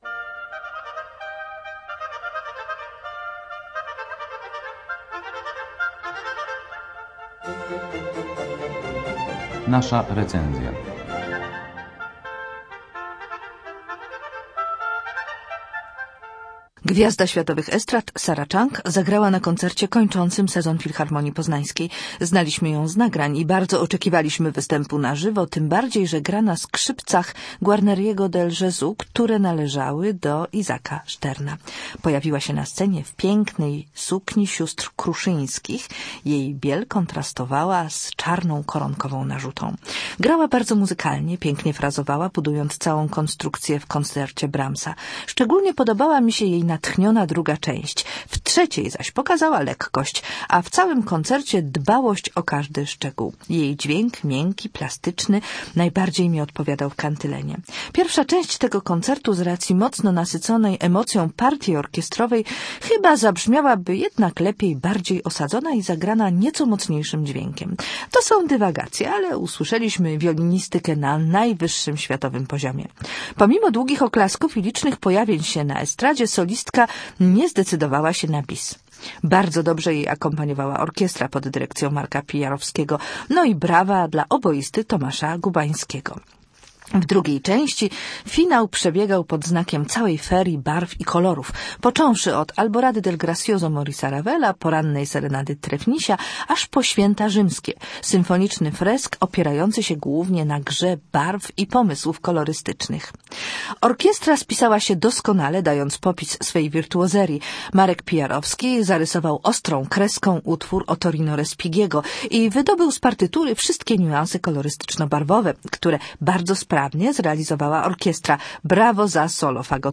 Sarah Chang, wielka gwiazda światowej wiolinistyki, wystąpiła w Auli UAM w piątek 17 czerwca, na zakończenie sezonu.